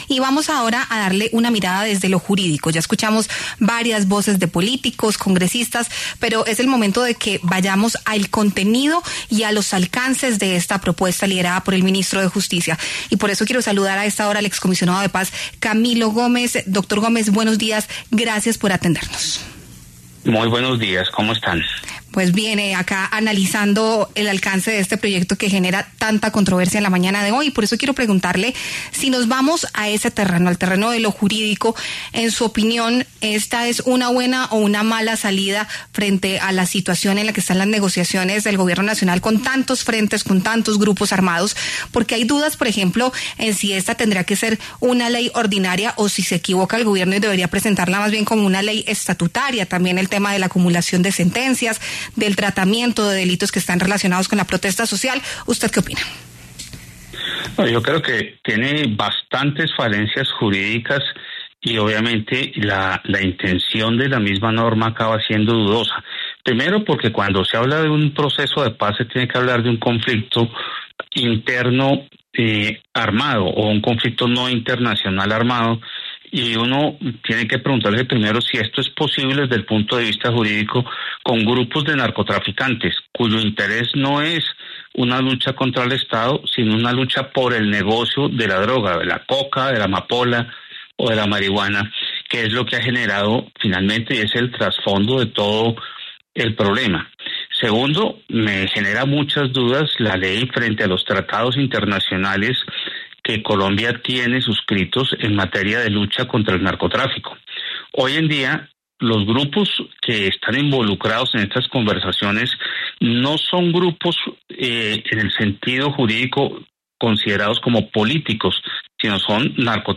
En entrevista con La W, el excomisionado de paz, Camilo Gómez, se pronunció ante el borrador del proyecto de paz total en el cual trabaja el Ministerio de Justicia.